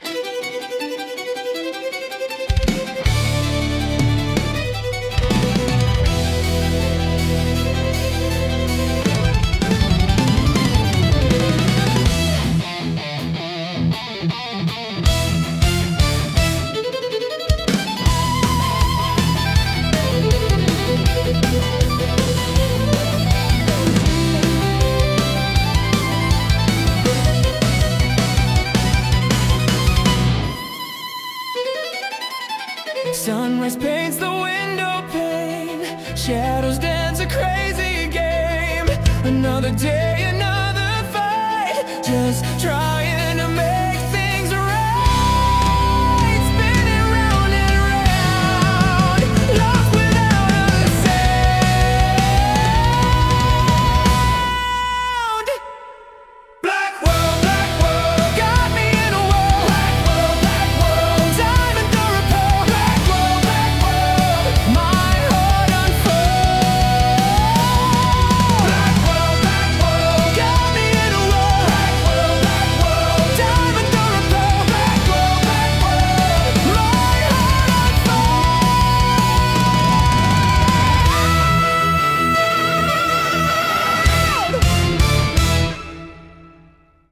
攻撃的なストリングスと、地を這うようなヘビーなドラムが融合した、超高速バイオリン・ロック！
ただ速いだけでなく、音の重厚感が凄まじく、フロアを圧倒するような「かっこよさ」があります。